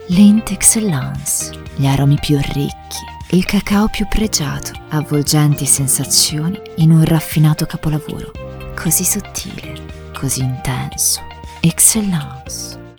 Commerciale, Profonde, Polyvalente, Amicale, Chaude
Commercial